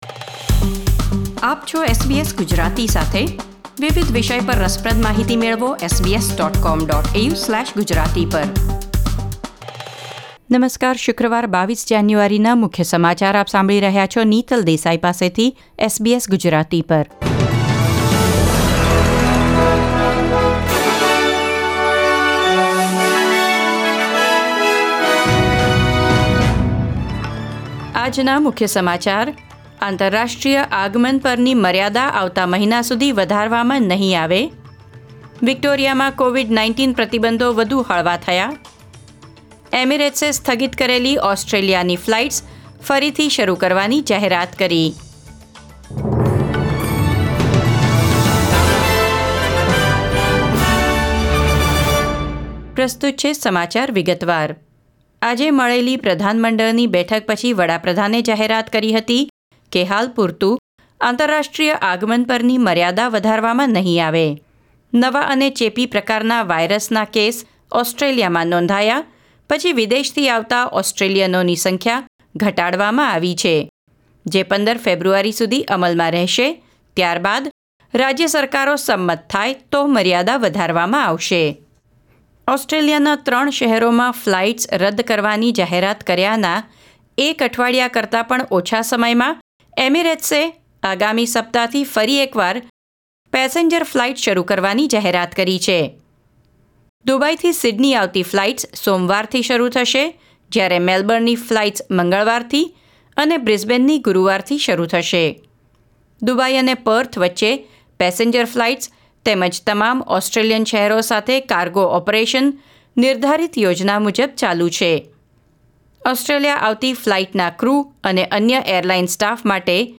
SBS Gujarati News Bulletin 22 January 2021